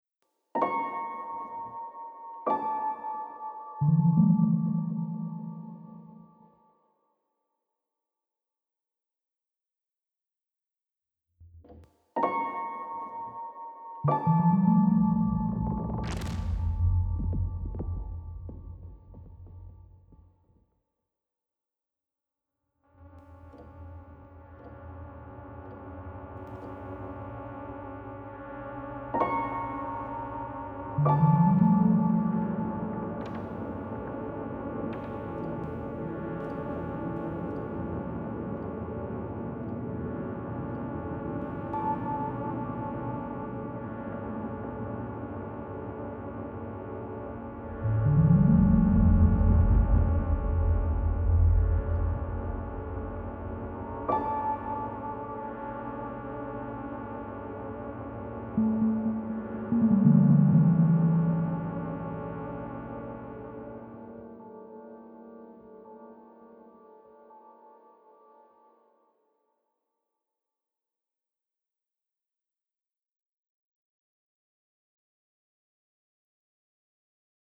Creepy suspenseful sparse underscore.